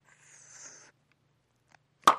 Bubble Gum, Blowing Bubbles & Popping.